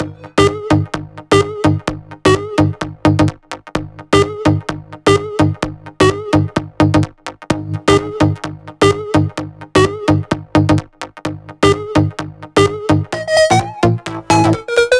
Index of /VEE/VEE2 Melody Kits 128BPM